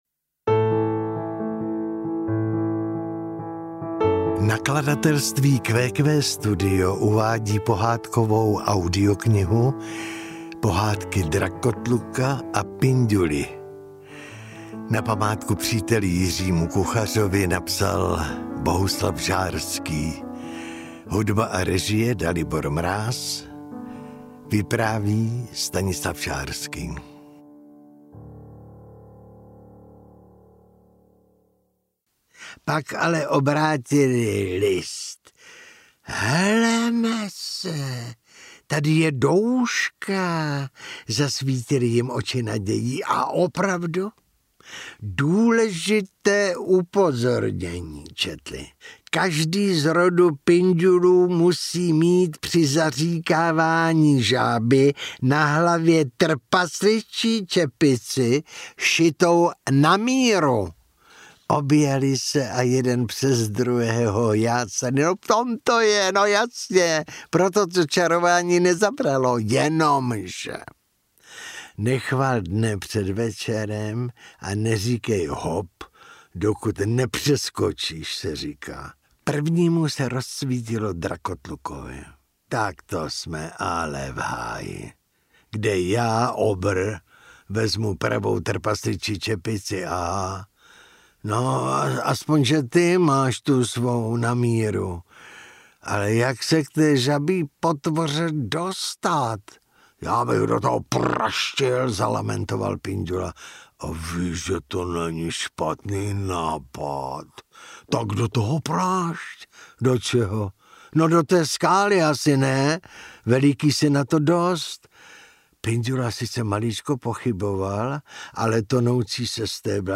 Pohádky Drakotluka a Pinďuly audiokniha
Ukázka z knihy